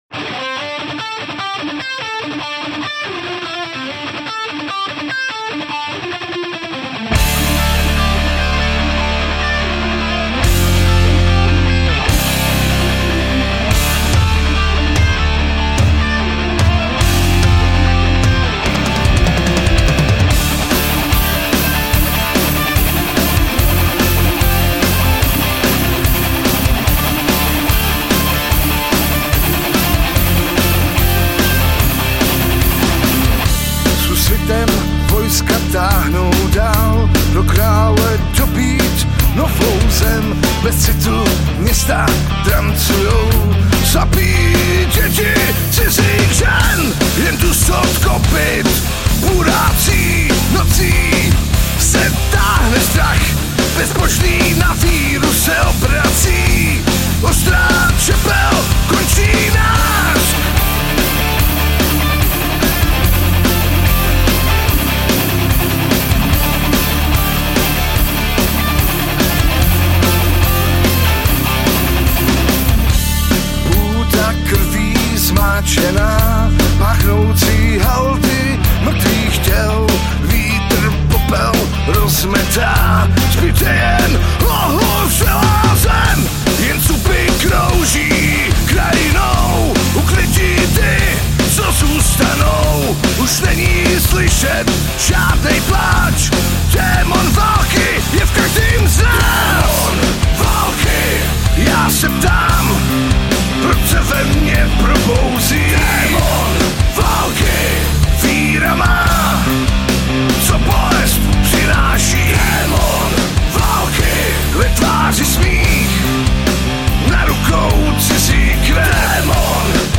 Žánr: Rock